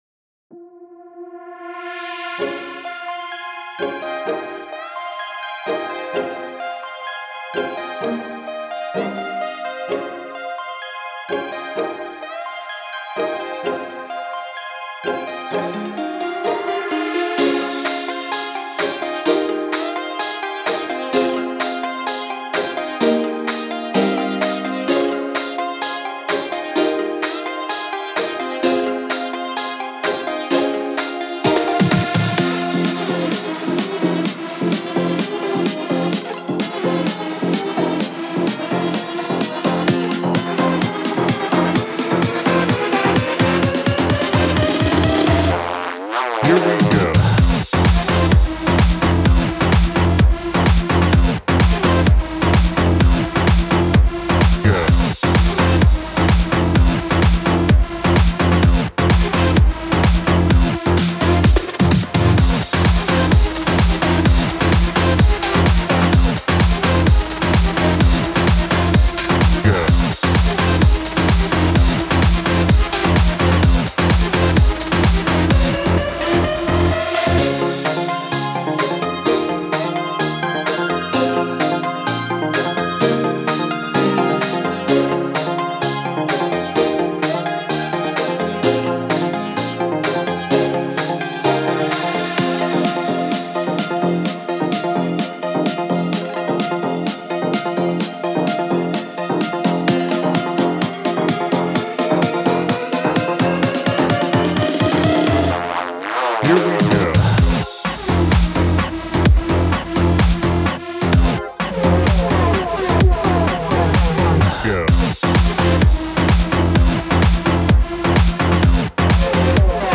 edm bass house